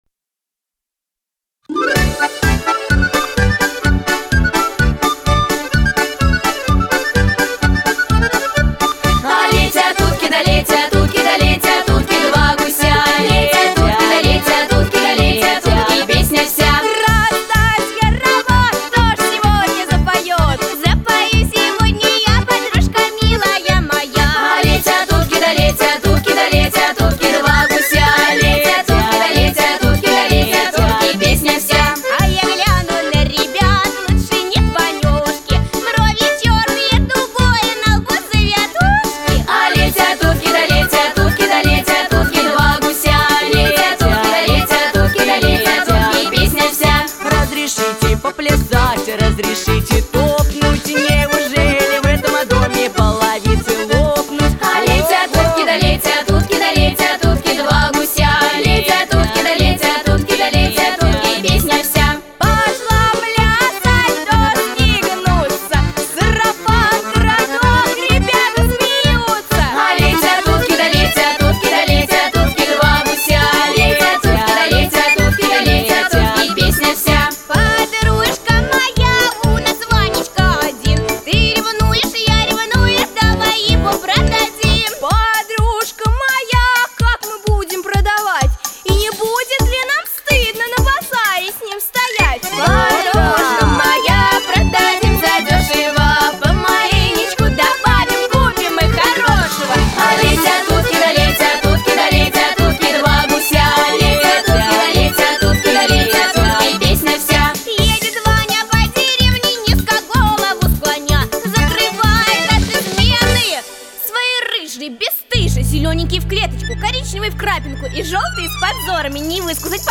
• Жанр: Детские песни
народный мотив
куплеты